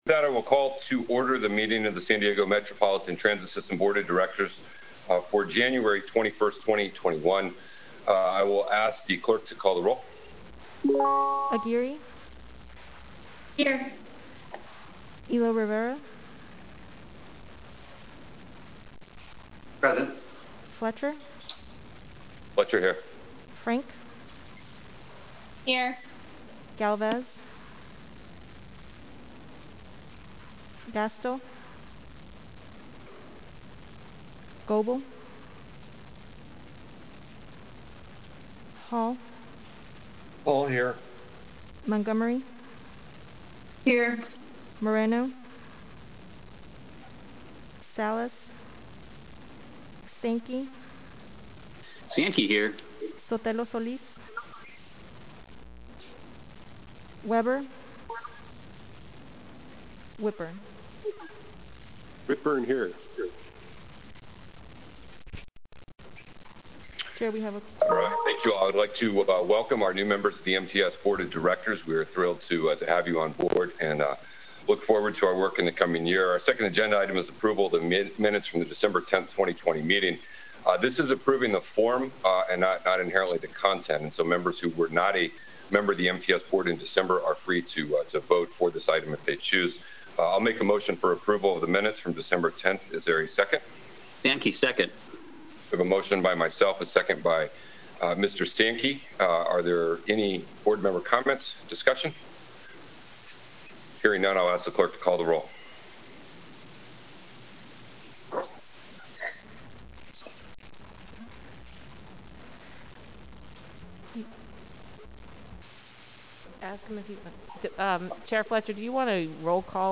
Meeting Type Board Meeting